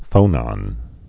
(fōnŏn)